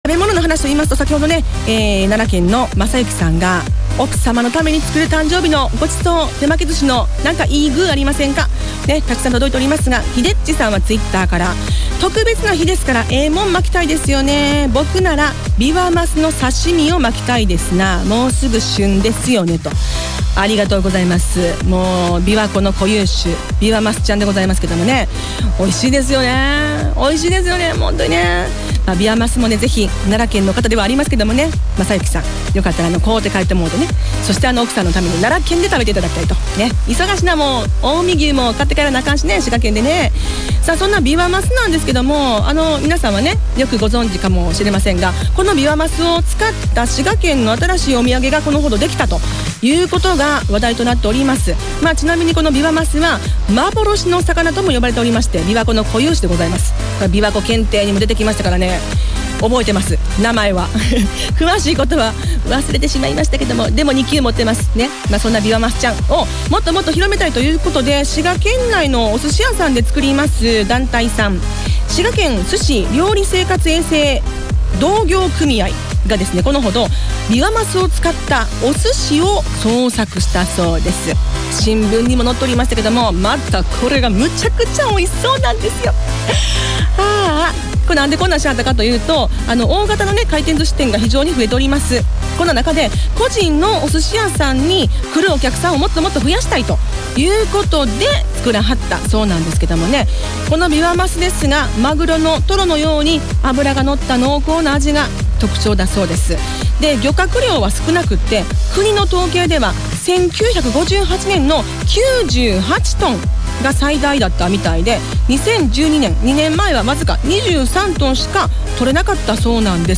琵琶マス寿しがFM滋賀e-radio ( 5/30(金）１１:５５分頃）で紹介放送されました。